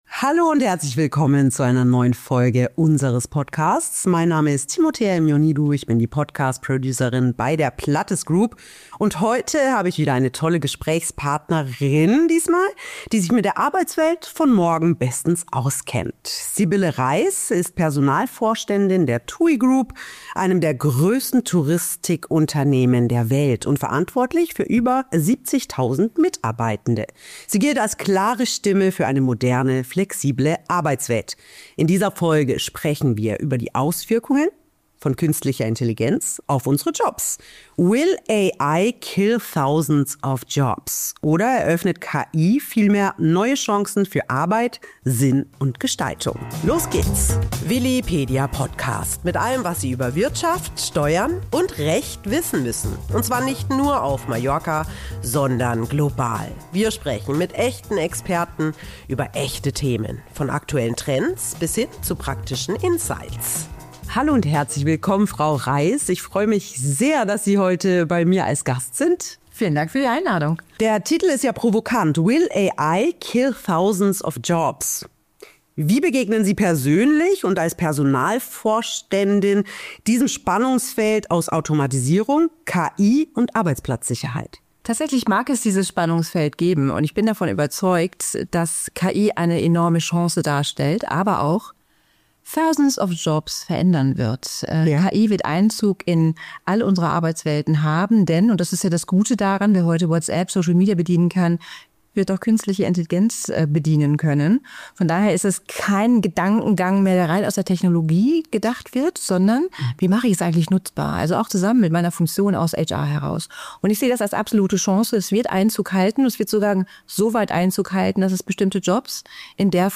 Interview ~ Willipedia – Wirtschaft, Steuern & Recht Podcast